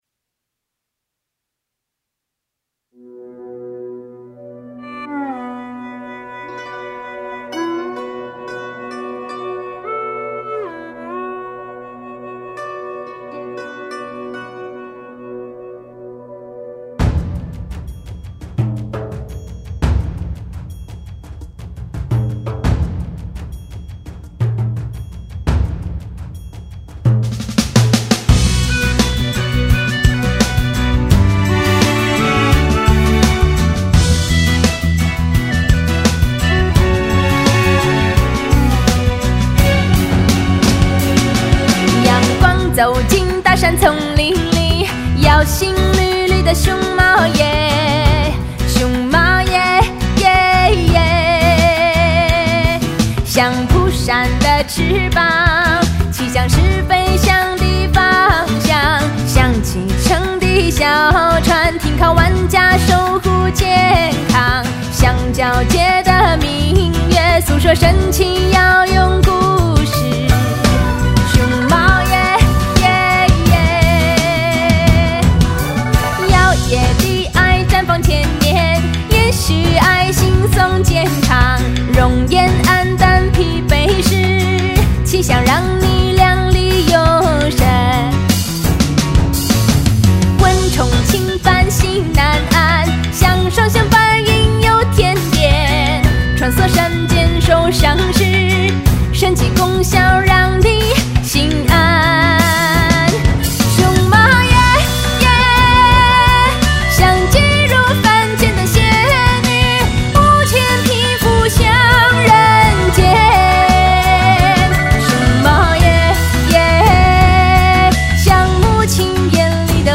质朴的歌词搭配欢快的曲风，朗朗上口的同时洋溢着快乐的气息